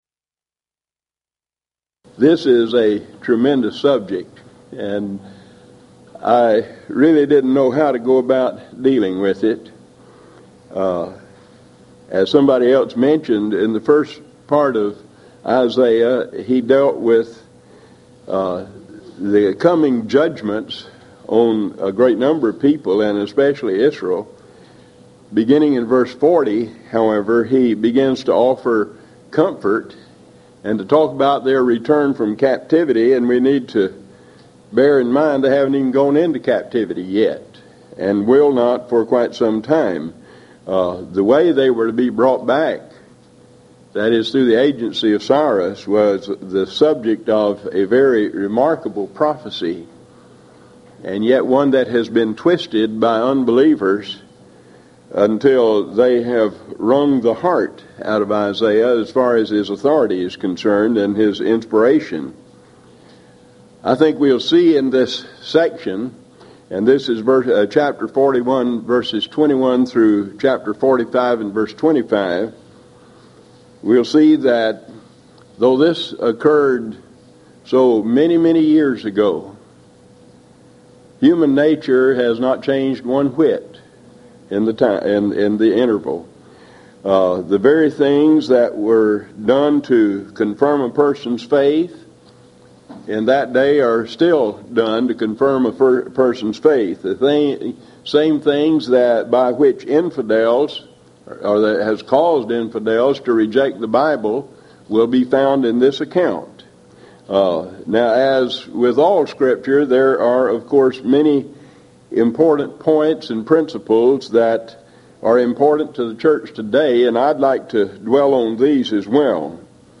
Series: Houston College of the Bible Lectures